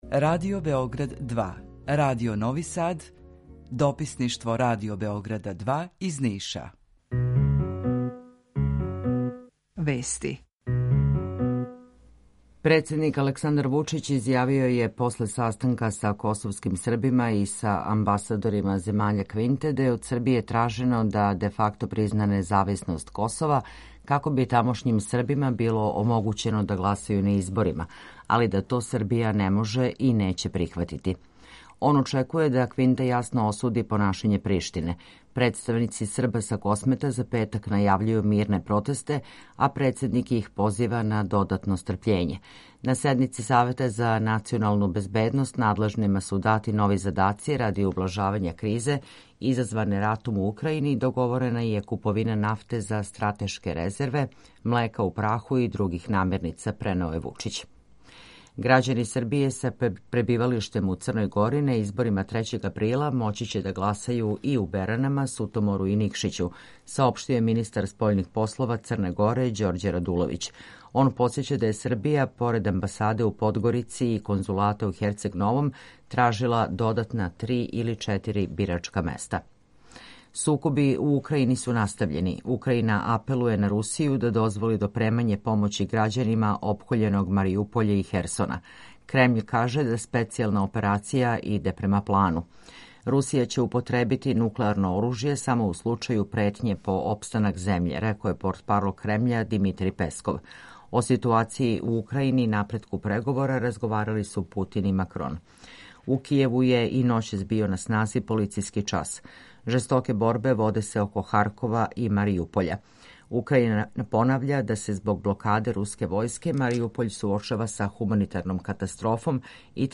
Укључење Грачанице
У два сата, ту је и добра музика, другачија у односу на остале радио-станице.